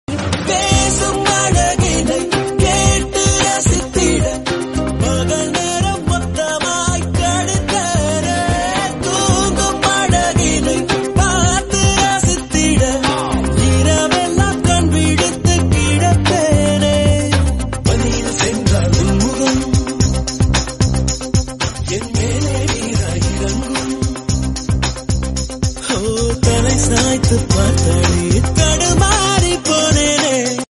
Best Ringtones, Tamil Ringtones